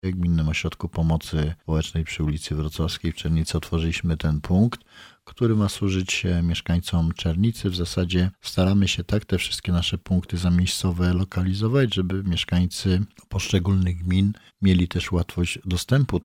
– W ostatnim czasie otworzyliśmy kolejny punkt udzielenia porad – podkreśla Roman Potocki.